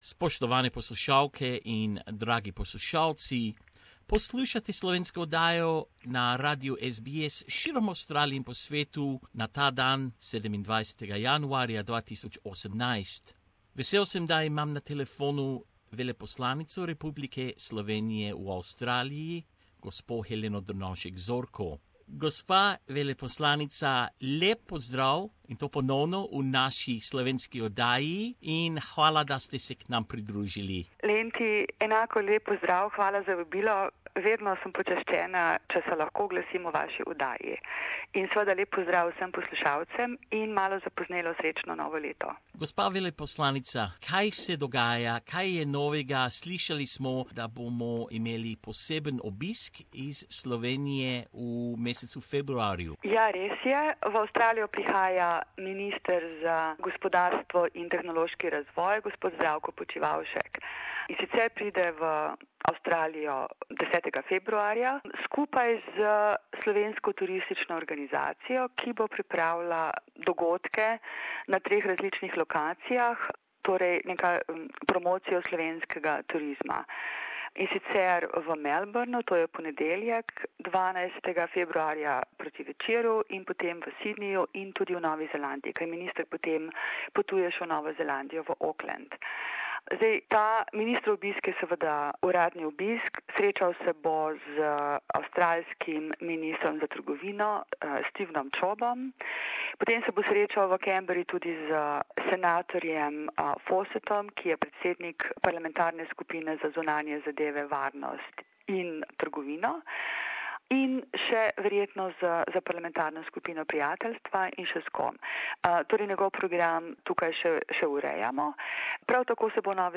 Gospa Helena Drnovšek Zorko, Veleposlanica Republike Slovenije v Avstraliji v pogovoru za slovensko oddajo Radia SBS.